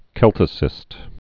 (kĕltĭ-sĭst, sĕl-)